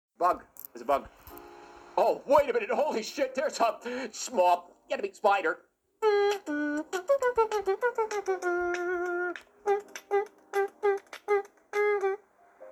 Small Enemy Spider Téléchargement d'Effet Sonore
Sound Effects Soundboard23 views